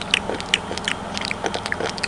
Quick Slurping Sound Effect
Download a high-quality quick slurping sound effect.
quick-slurping.mp3